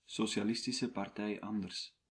Nl-Socialistische_Partij_Anders.ogg.mp3